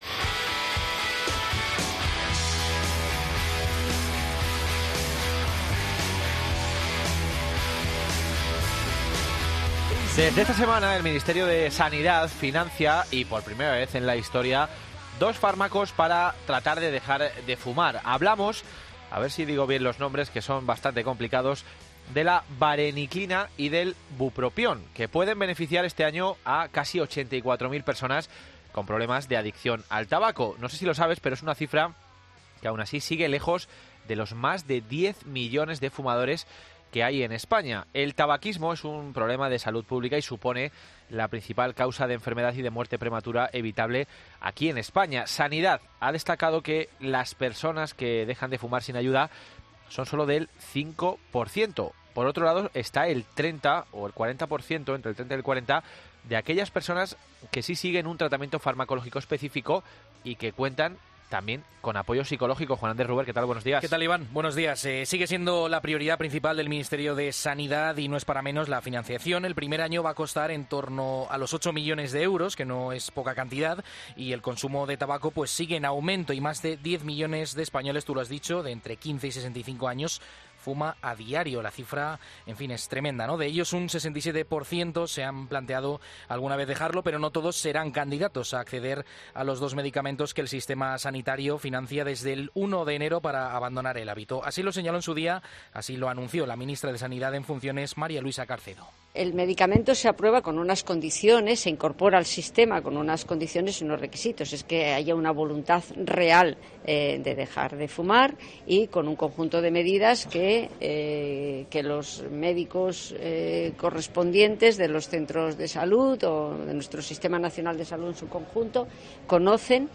Por otro lado, también hemos salido a la calle para conocer qué le parece a la gente que el Ministerio de Sanidad financie estos fármacos.